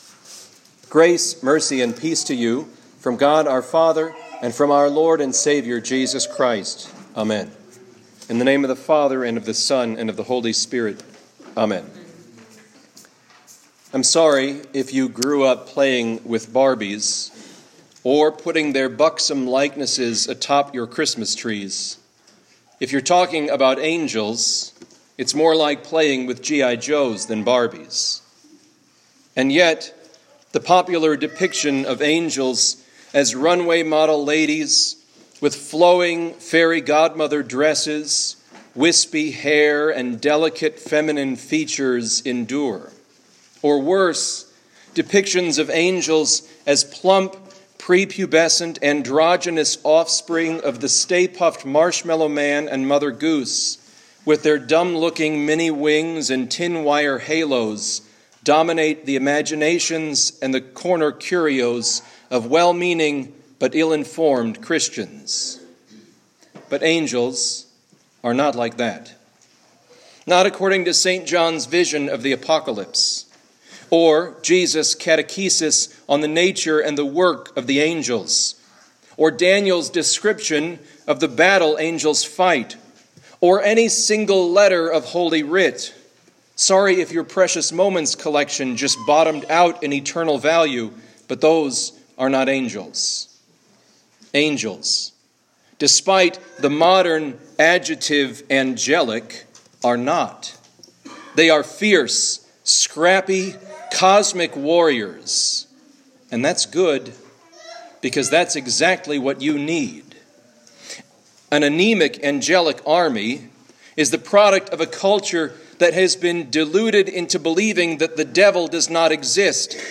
Home › Sermons › St. Michael & All Angels